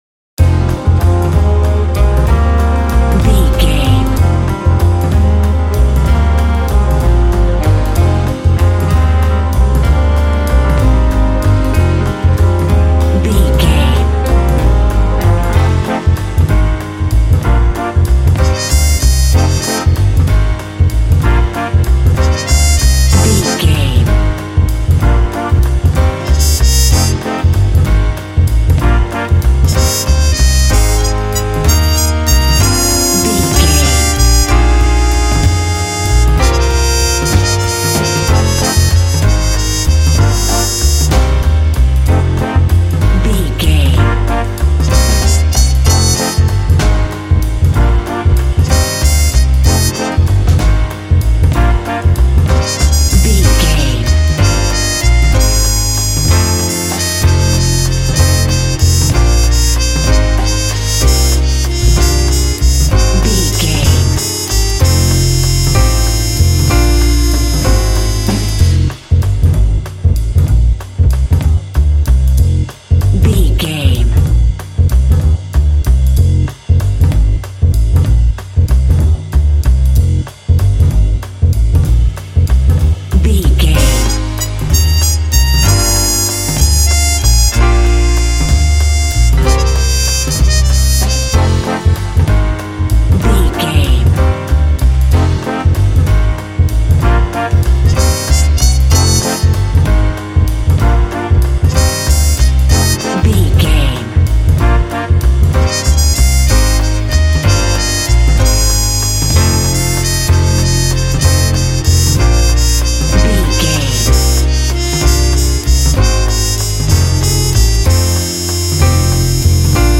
Smooth jazz track reminiscent of classic jazz standards.
Aeolian/Minor
melancholy
smooth
saxophone
double bass
drums
brass
piano
jazz
swing